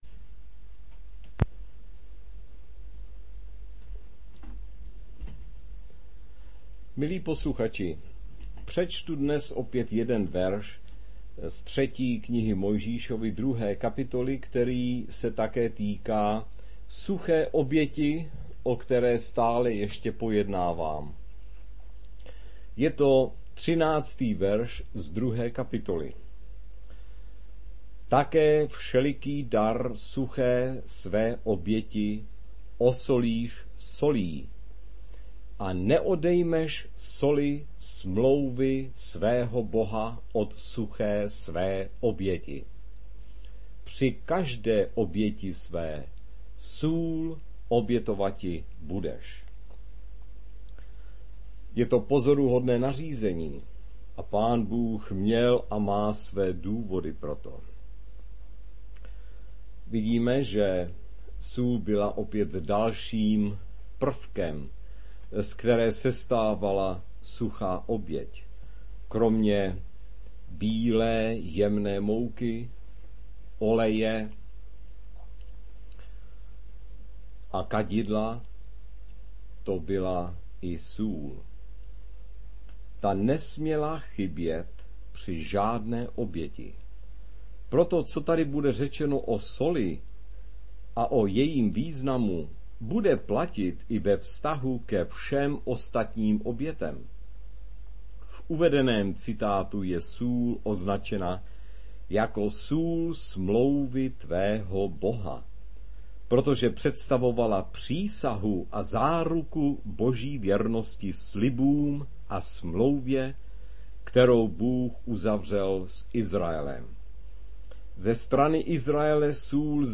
Audio proslovy